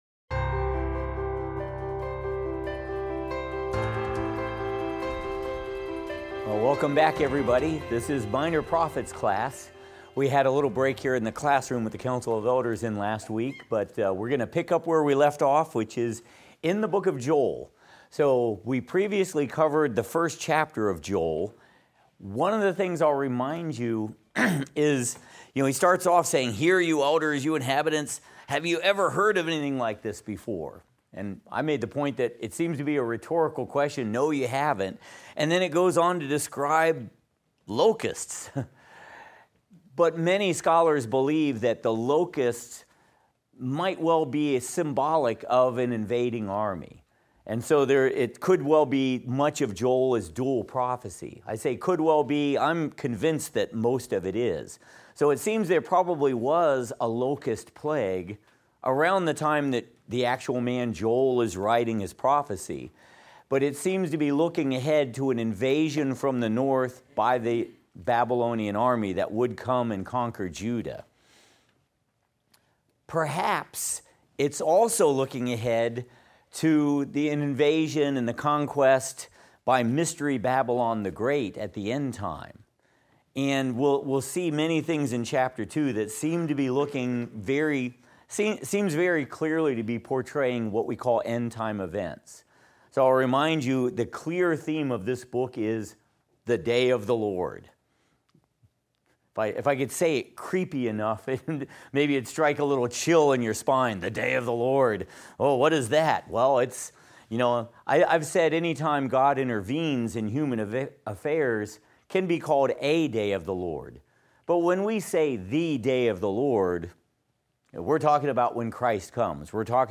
Minor Prophets - Lecture 7 - audio.mp3